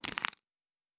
Fantasy Interface Sounds
Cards Shuffle 1_08.wav